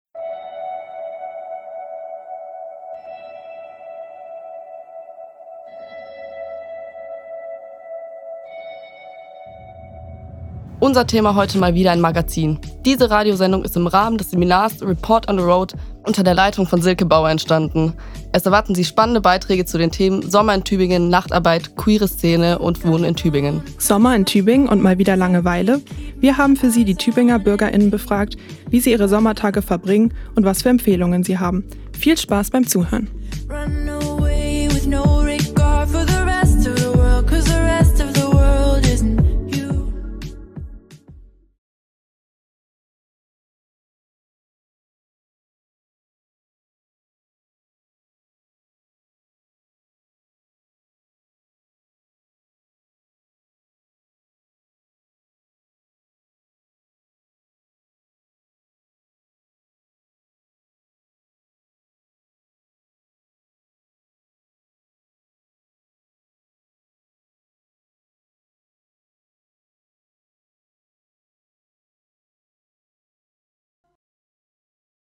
In einer Straßenumfrage berichten Passant*innen, es sei sehr schwierig bezahlbaren Wohnraum zu finden.